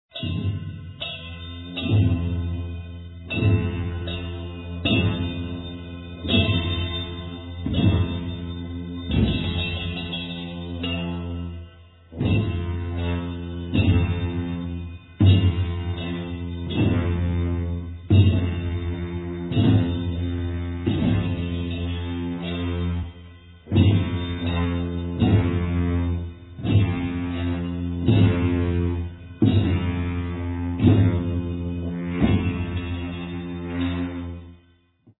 Richly diverse recordings of incredible sonic fidelity!